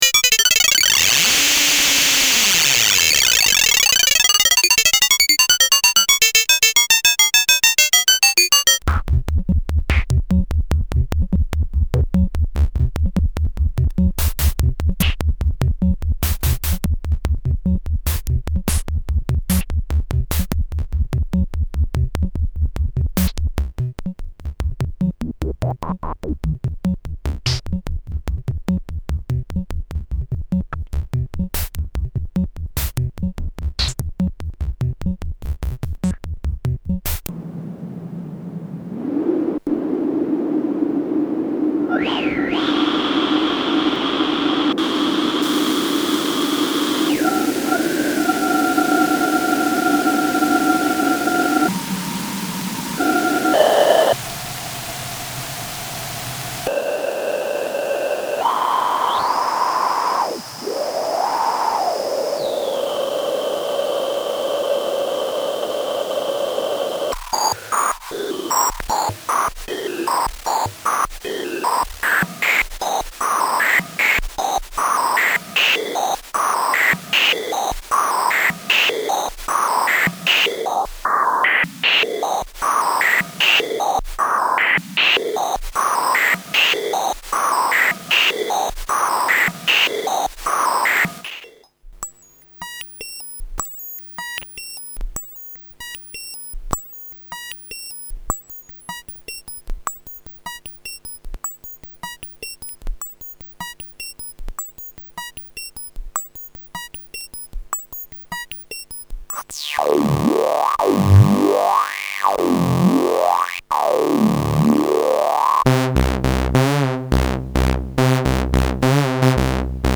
CS30 Analog Synthesizer + analog step sequencer
The CS30 has a nice little 8Step Sequencer.
SOUND very good for special sounds and glitch - sounds for the sound freaks like aphex twin etc.
SOUND nicht der basshammer aber sehr gut für frickelklänge und sachen a la warp..
CS30 Frickel-Demo
cs30_demo.mp3